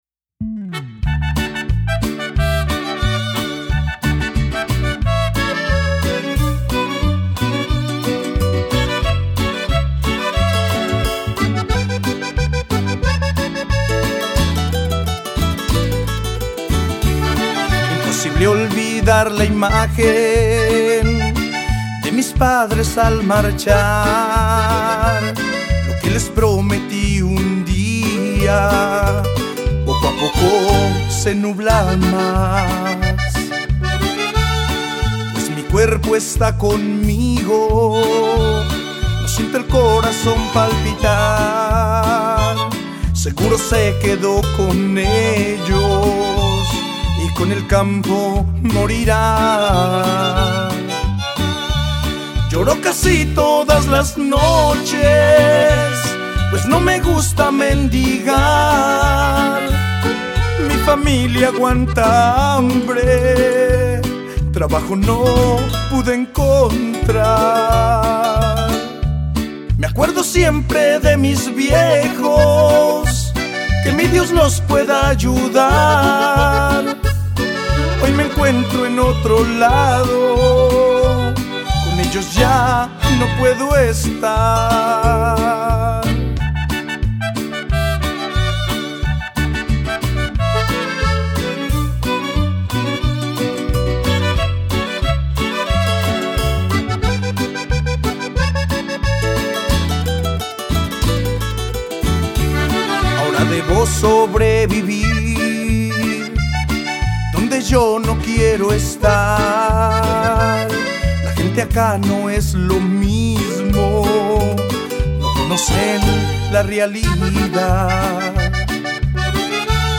Canción
trompeta.
guitarra y requinto.
bajo, acordeón
violín.